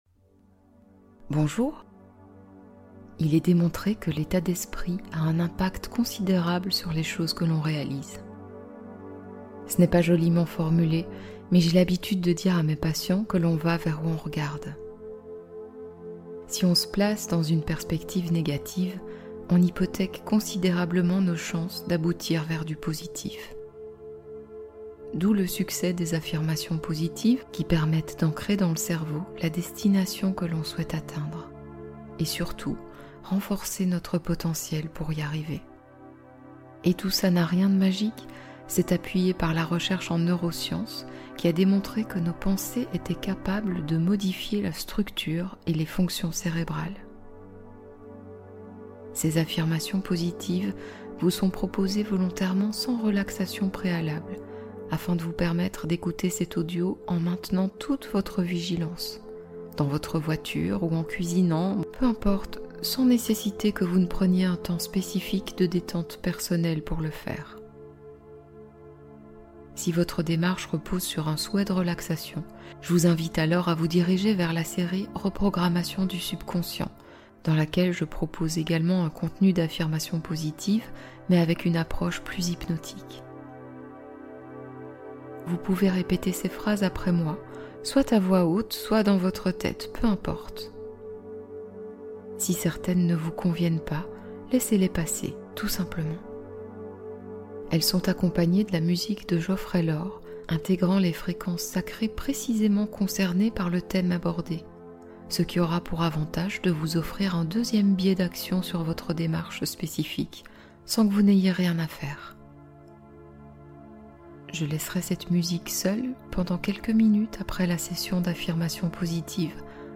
Calmez stress et anxiété instantanément | Affirmations et fréquences apaisantes